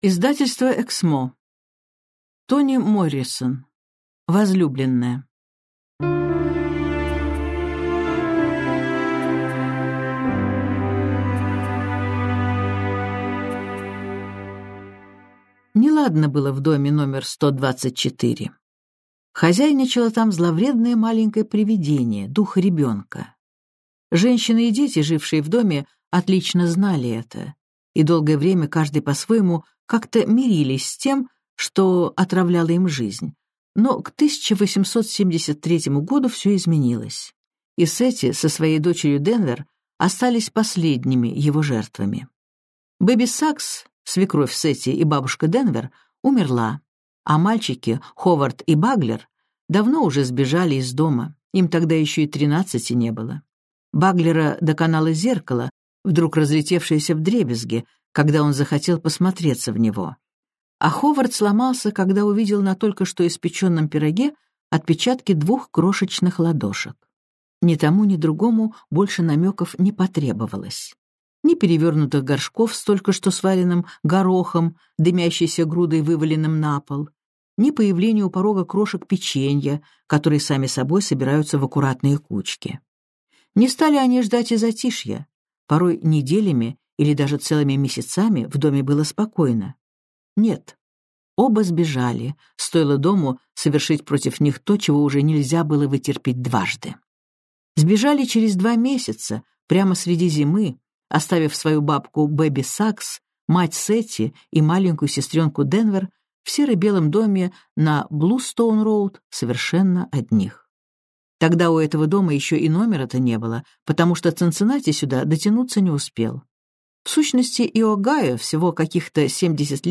Аудиокнига Возлюбленная | Библиотека аудиокниг